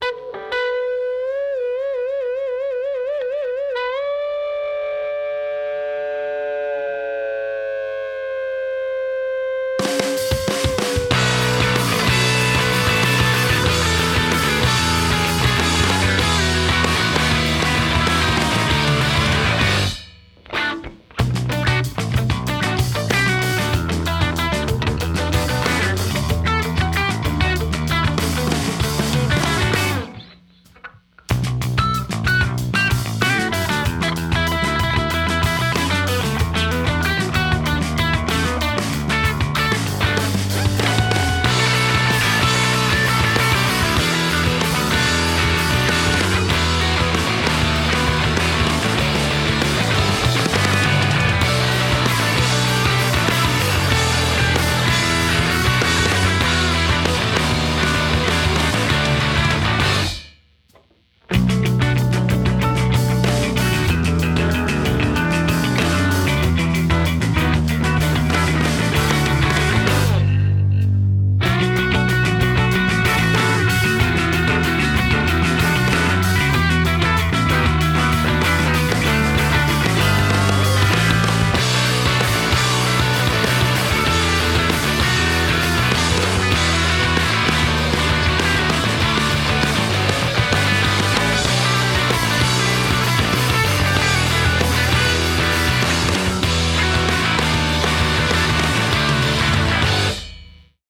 Instrumental punk rock
punk rock See all items with this value